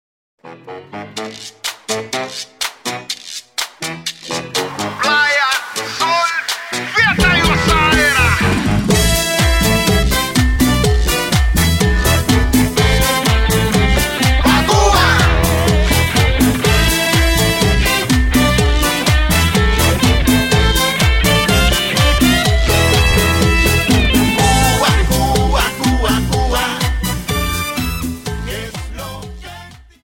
Dance: Cha Cha Song